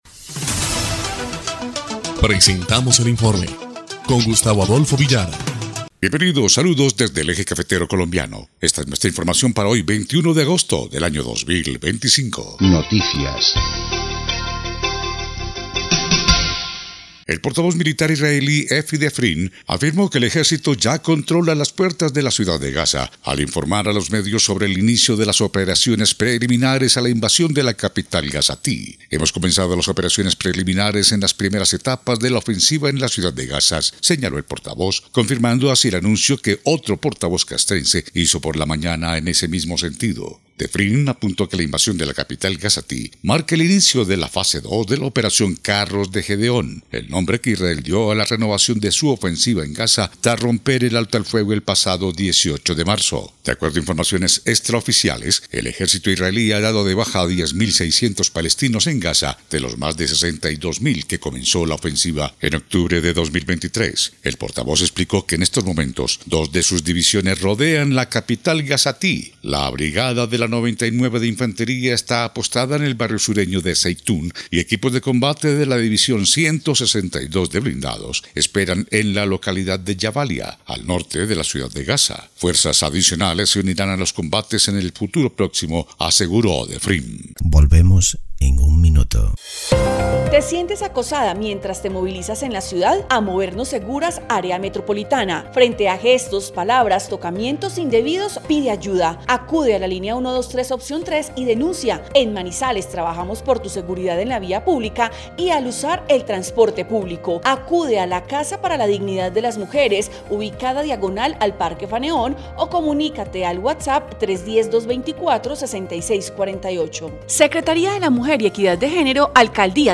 EL INFORME 2° Clip de Noticias del 21 de agosto de 2025